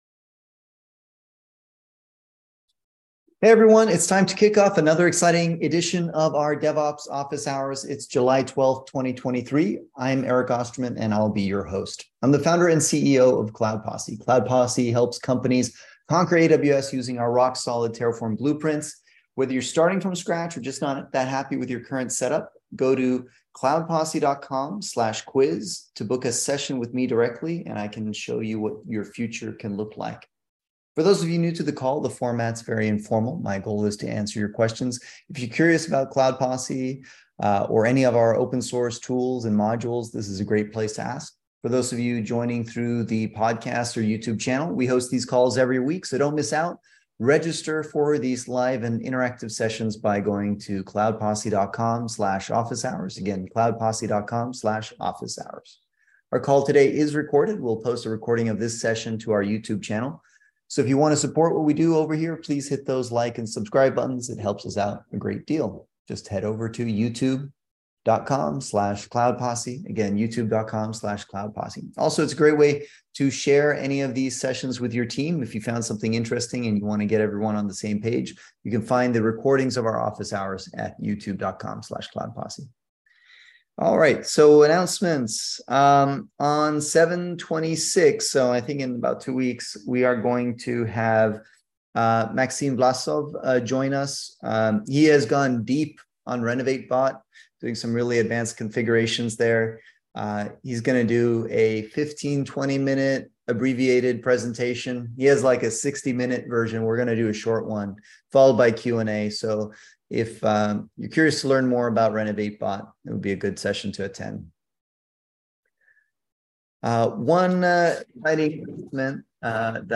Cloud Posse holds public "Office Hours" every Wednesday at 11:30am PST to answer questions on all things related to DevOps, Terraform, Kubernetes, CICD. Basically, it's like an interactive "Lunch & Learn" session where we get together for about an hour and talk shop.